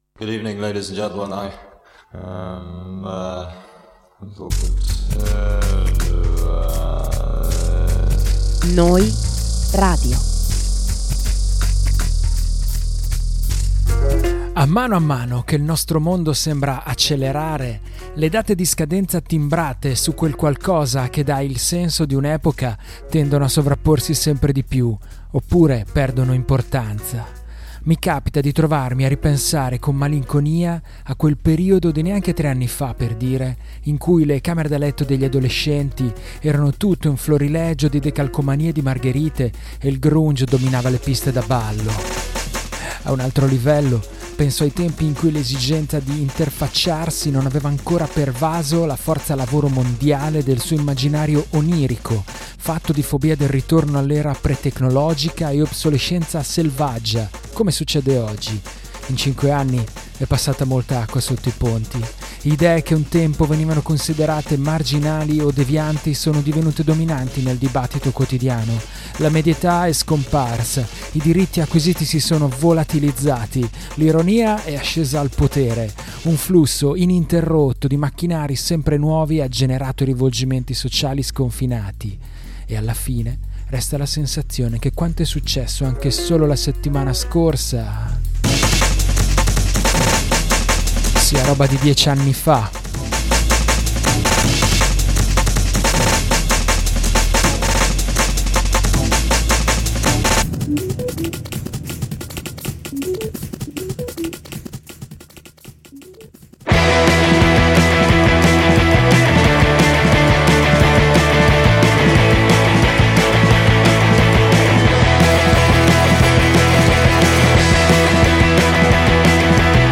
Podcast di novità indiepop, indie rock, shoegaze, post-punk, lo-fi e twee!